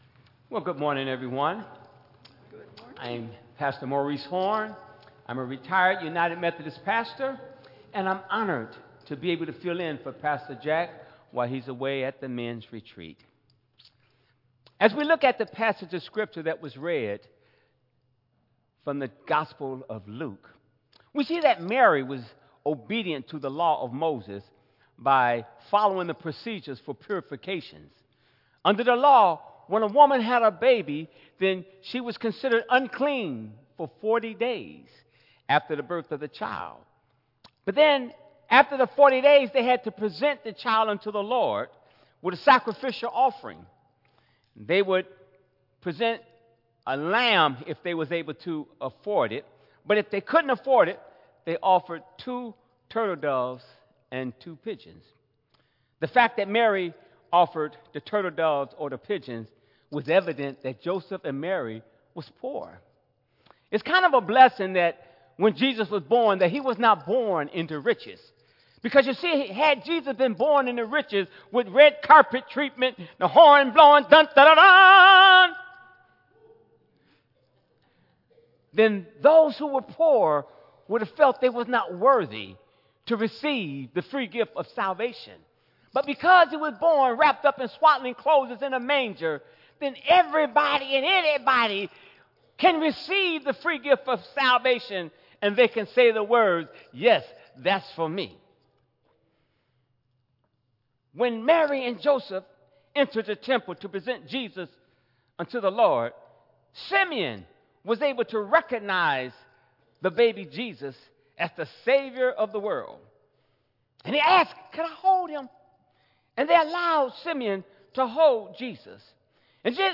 Tagged with Central United Methodist Church , Michigan , Sermon , Waterford , Worship